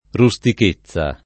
rustichezza [ ru S tik %ZZ a ]